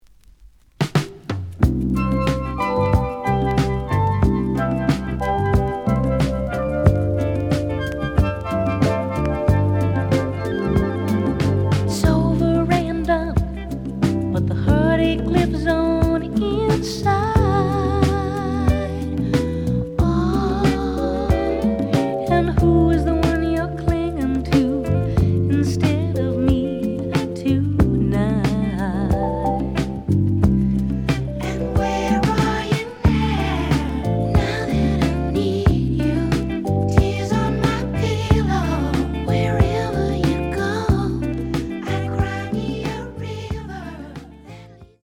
試聴は実際のレコードから録音しています。
The audio sample is recorded from the actual item.
●Genre: Soul, 70's Soul